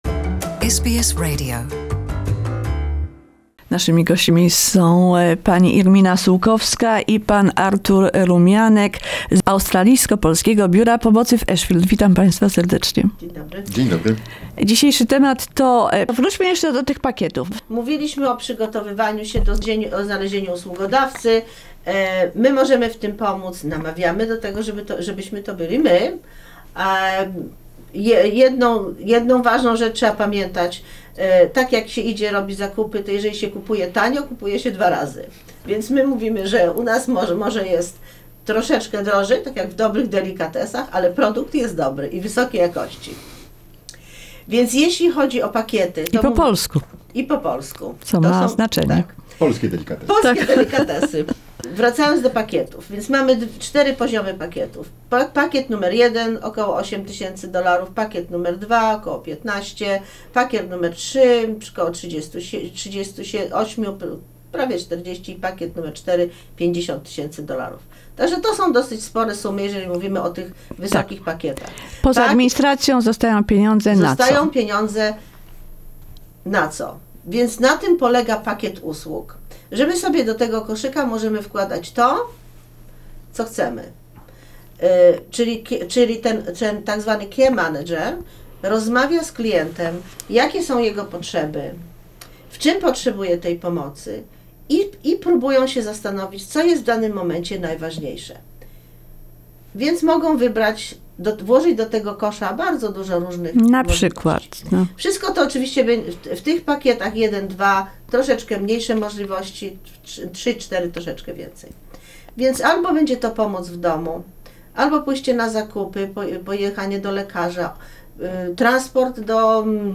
This is part 4 of the interview.